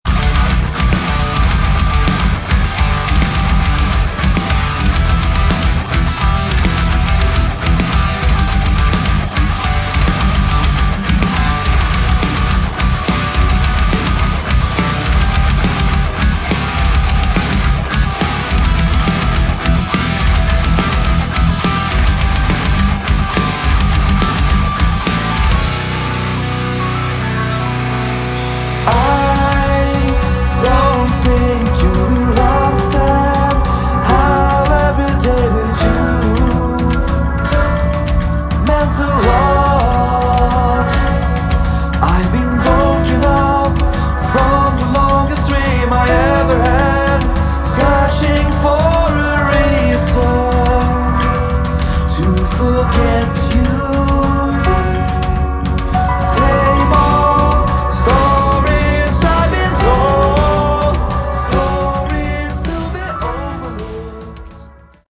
Category: Progressive Metal